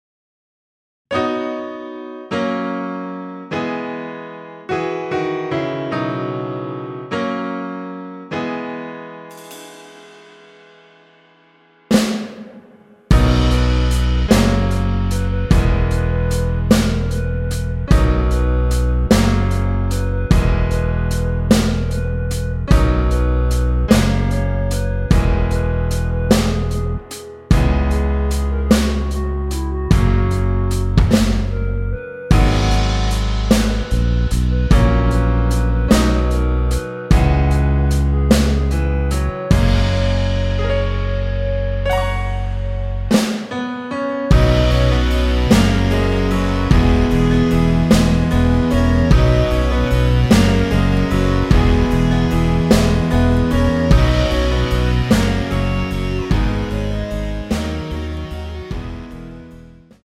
원키에서(-1)내린 멜로디 포함된 MR입니다.
◈ 곡명 옆 (-1)은 반음 내림, (+1)은 반음 올림 입니다.
멜로디 MR이라고 합니다.
앞부분30초, 뒷부분30초씩 편집해서 올려 드리고 있습니다.
중간에 음이 끈어지고 다시 나오는 이유는